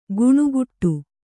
♪ guṇuguṭṭu